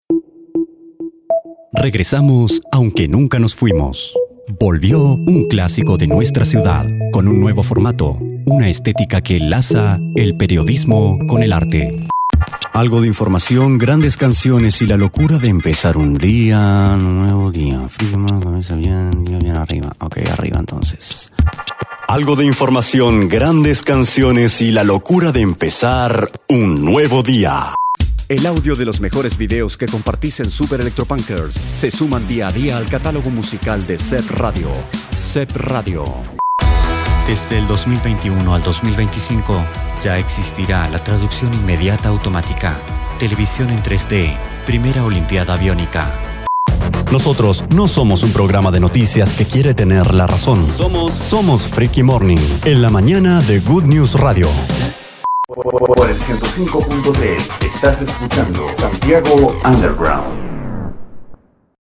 Locutor Voz para Institucionales, comerciales, radio, tv, cine.
Sprechprobe: Sonstiges (Muttersprache):